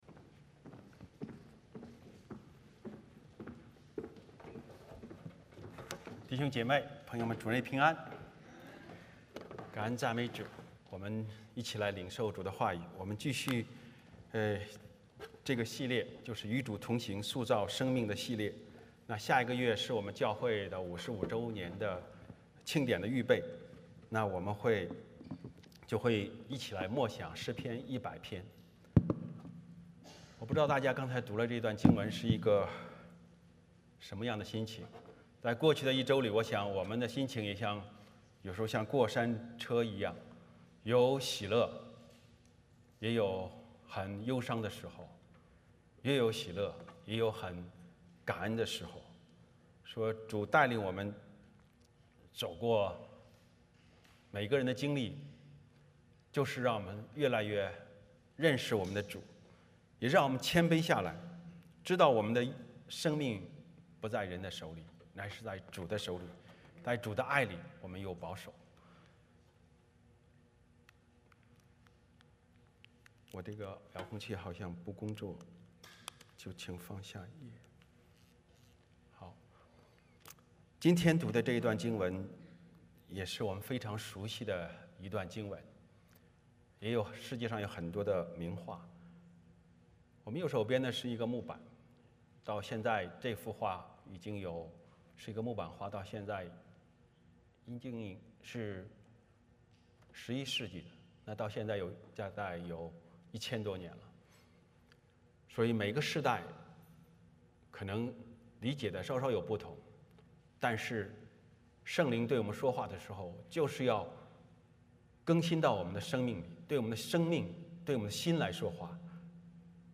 欢迎大家加入我们国语主日崇拜。
创世记 4:1-16 Service Type: 主日崇拜 欢迎大家加入我们国语主日崇拜。